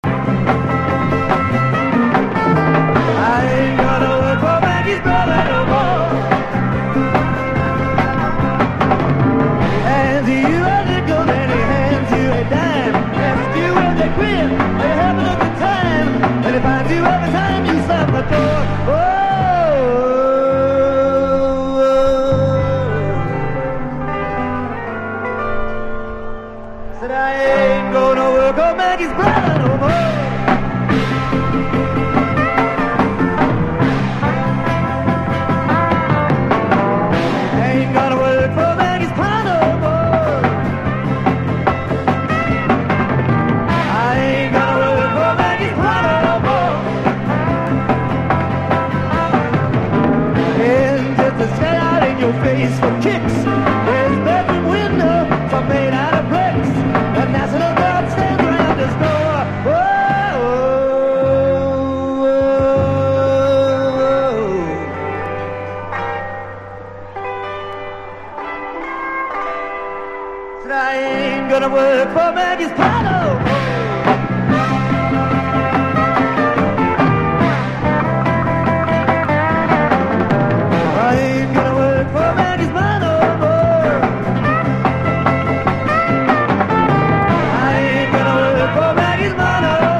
1. 70'S ROCK >
ロック･テイストが強いライブでパワフルで迫力のある演奏を堪能できます。